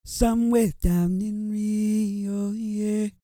E-CROON 3012.wav